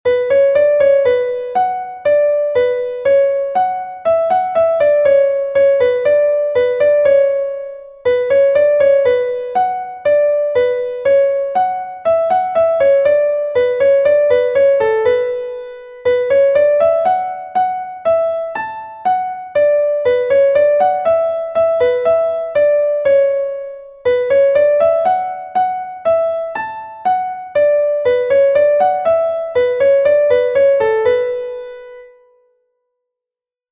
Saint Patrick's An dro is a An dro from Brittany